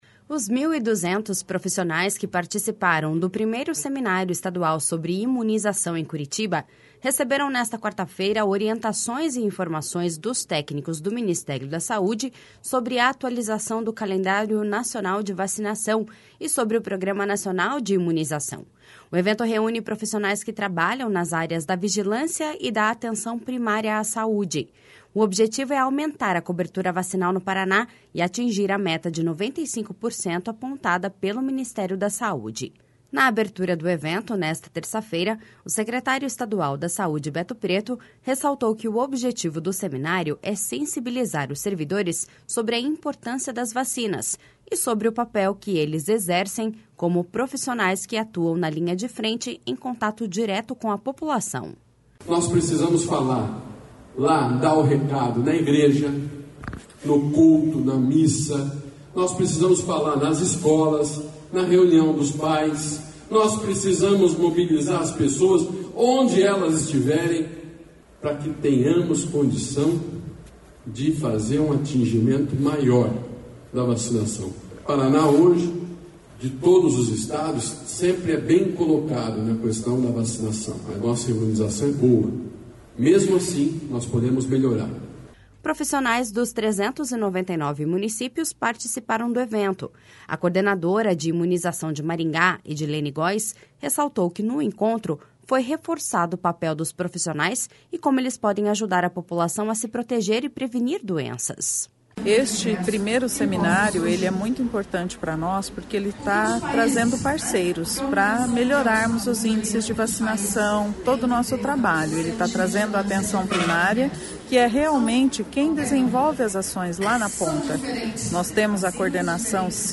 O objetivo é aumentar a cobertura vacinal no Paraná e atingir a meta de 95% apontada pelo Ministério da Saúde. Na abertura do evento nesta terça-feira, o secretário estadual da Saúde, Beto Preto, ressaltou que o objetivo do Seminário é sensibilizar os servidores sobre a importância das vacinas e sobre o papel que eles exercem como profissionais que atuam na linha de frente, em contato direto com a população.// SONORA BETO PRETO.//